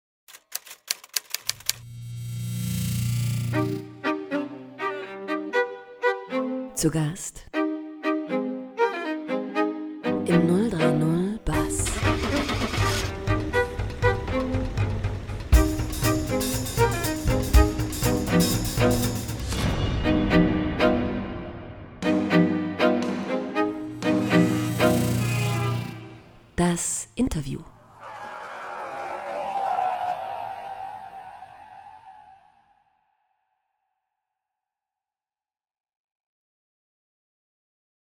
Jingle RadioBuzzzBerlin